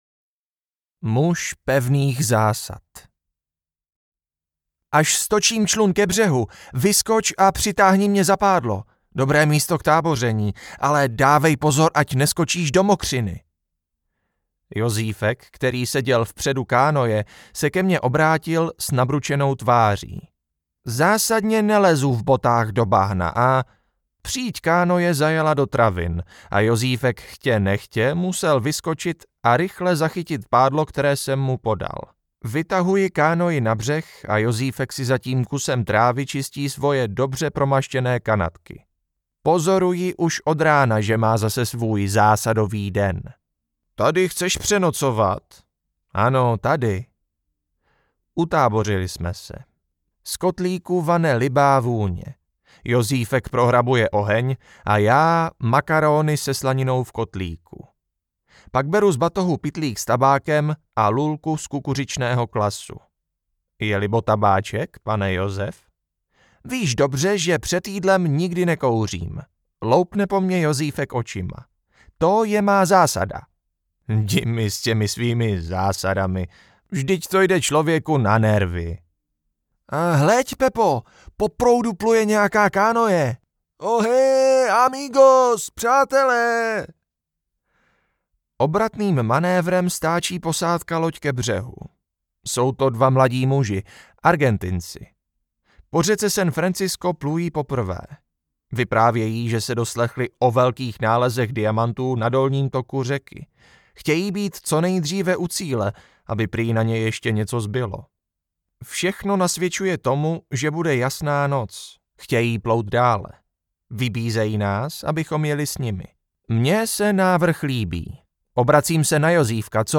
Na vlně 57 metrů audiokniha
Ukázka z knihy